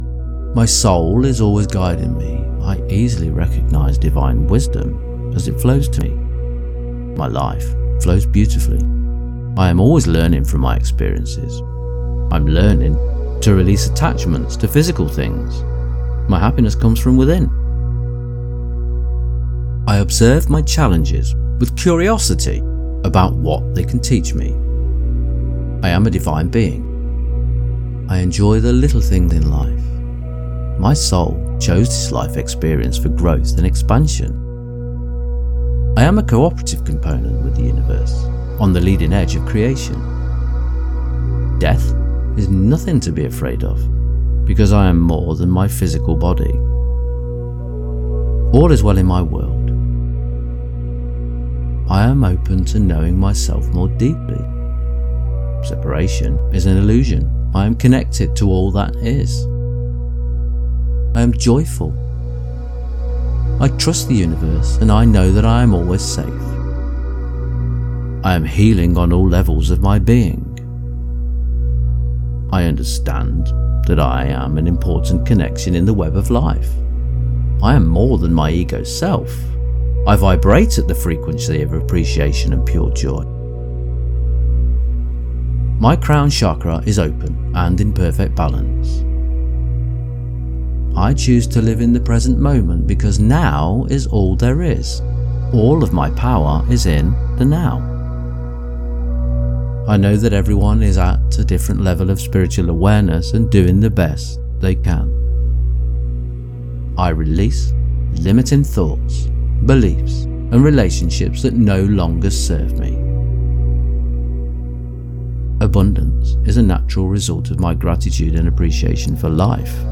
Connect with the Divine | Awaken Your Higher Self with 100 Affirmations | 963 Hz | Emotional Healing - Dynamic Daydreaming
963-affirmations.mp3